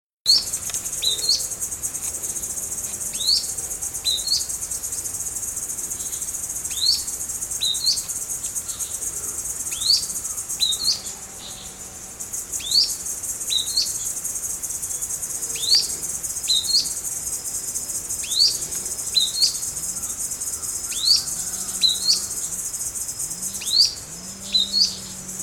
Grey-bellied Spinetail (Synallaxis cinerascens)
Life Stage: Adult
Province / Department: Misiones
Location or protected area: Campo Ramón
Condition: Wild
Certainty: Photographed, Recorded vocal